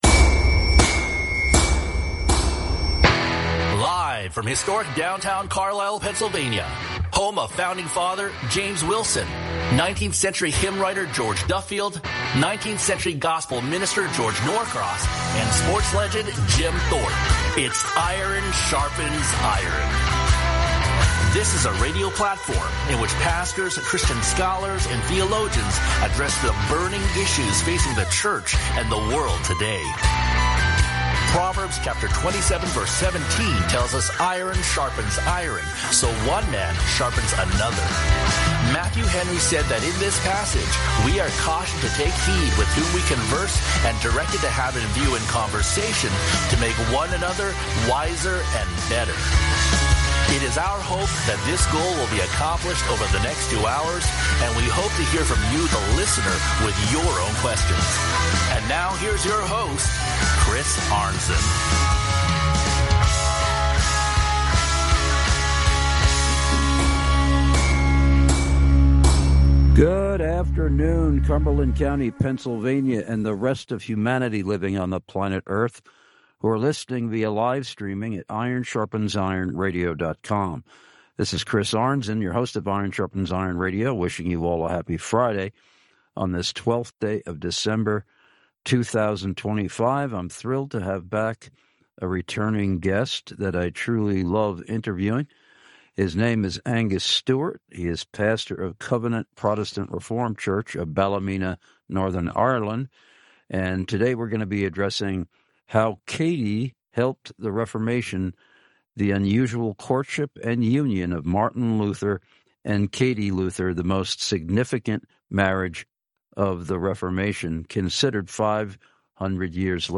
Lectures/Debates/Interviews